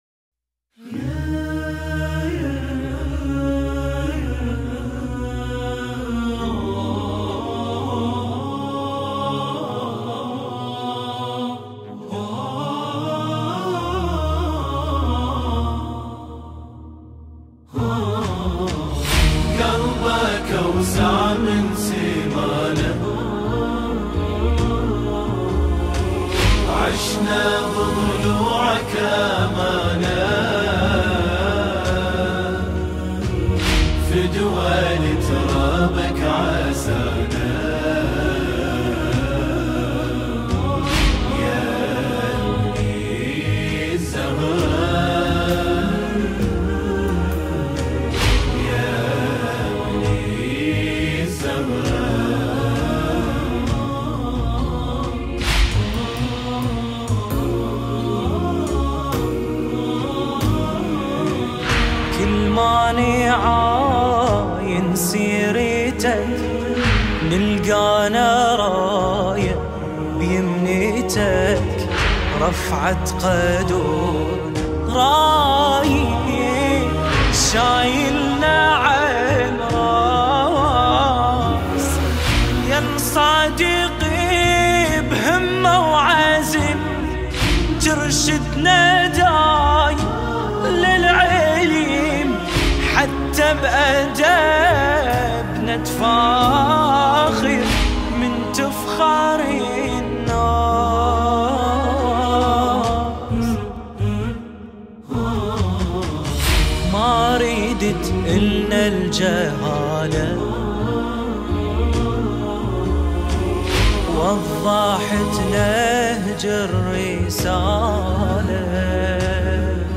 الأهات
الکورال